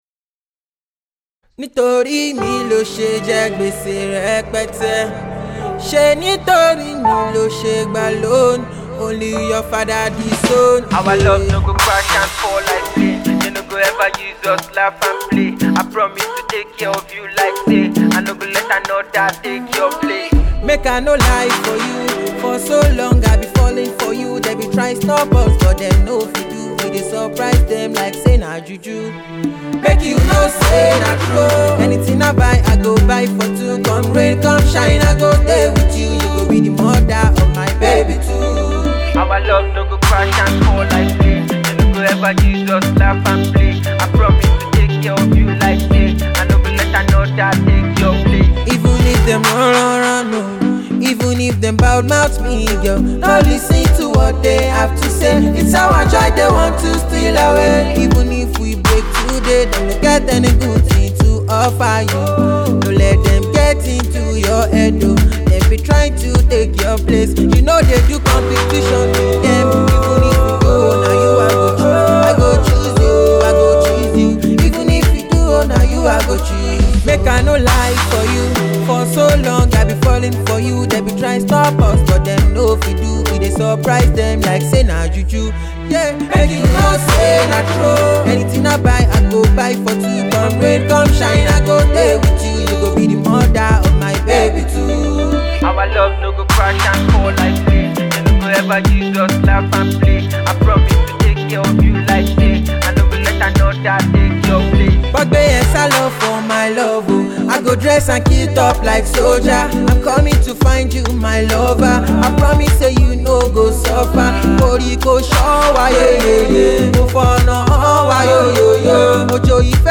A beautiful love song that will blow your mind